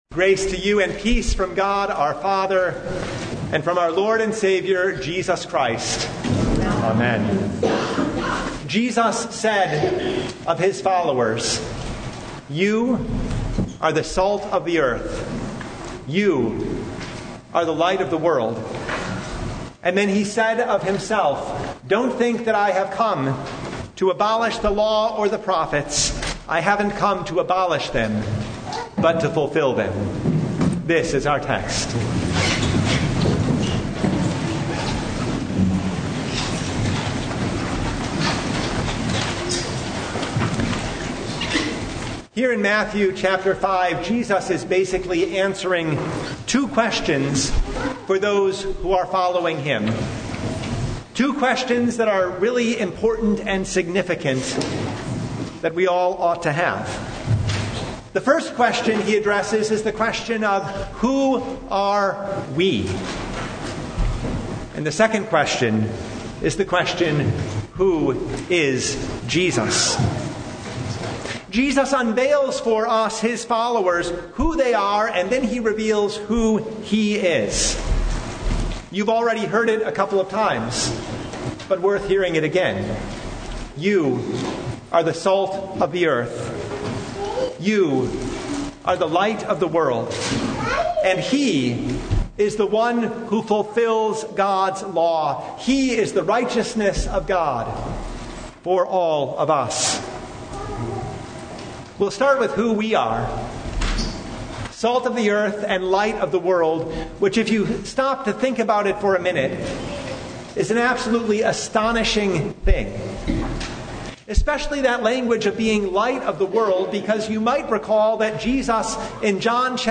Sermon for February 8, 2026
Sermon Only « The Two Realms